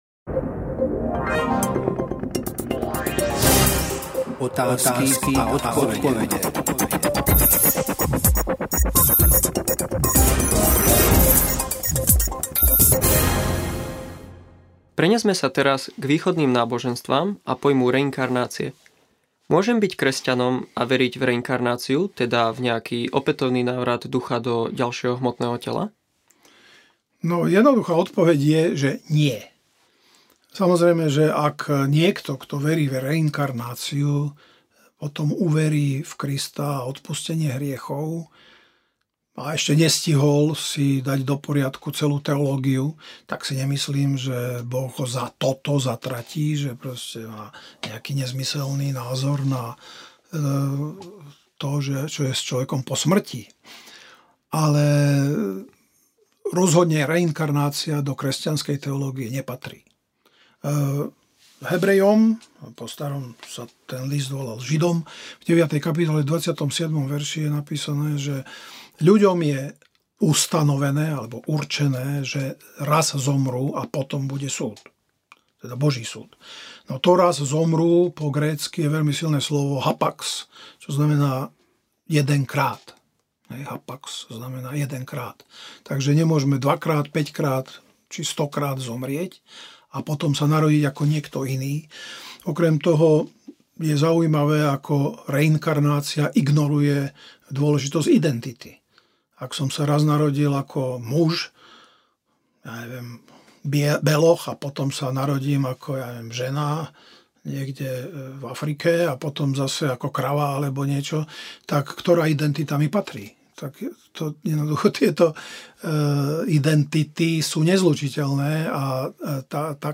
Zvukový archív